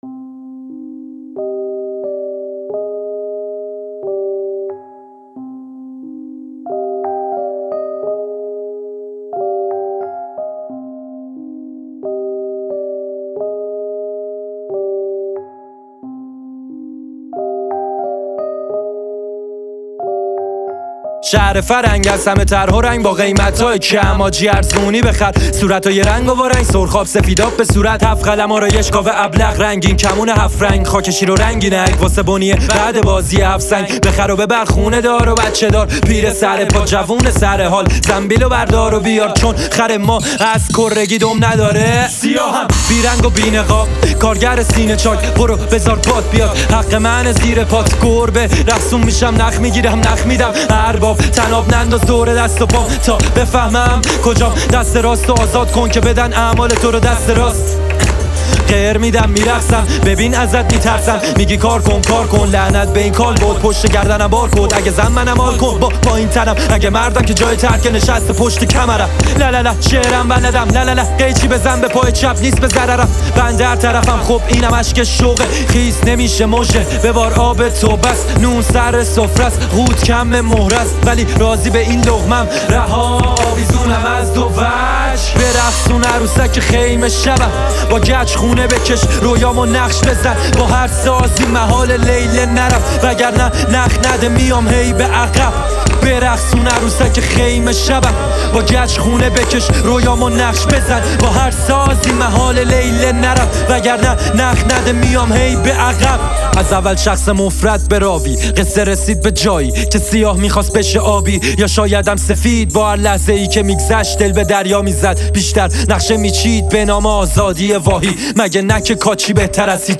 رپفارسی